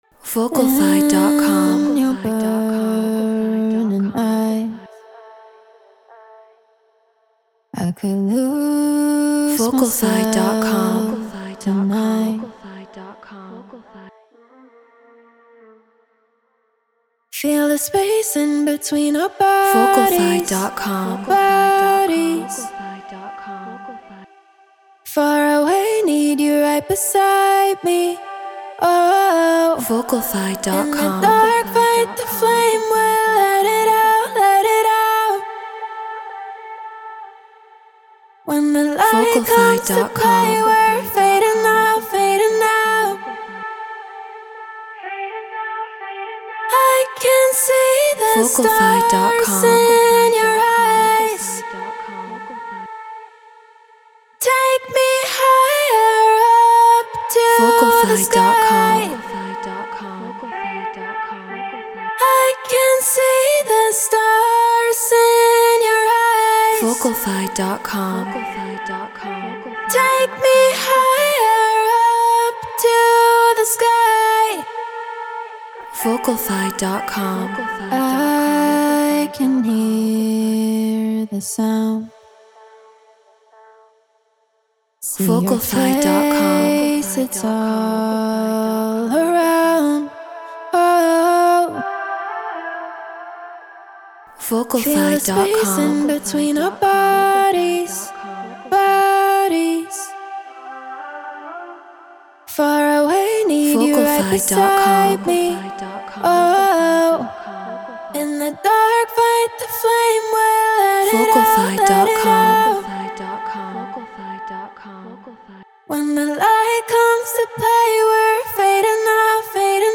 House 124 BPM Dmin